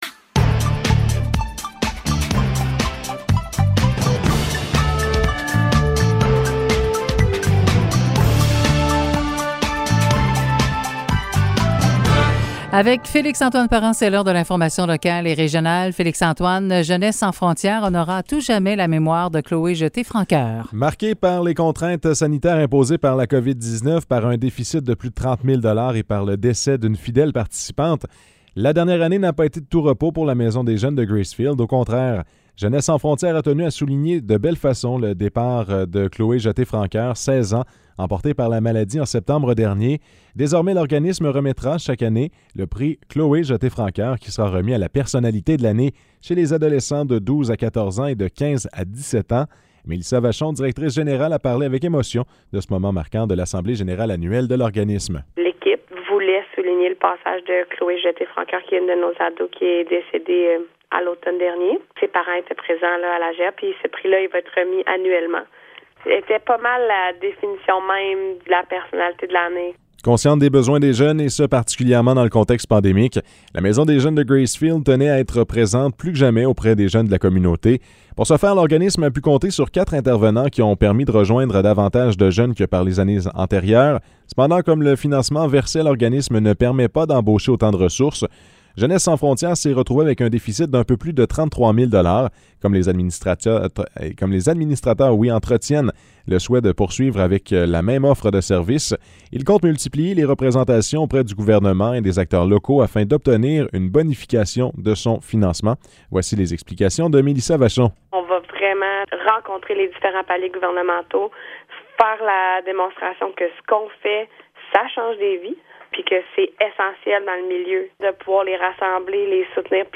Nouvelles locales - 22 juin 2022 - 10 h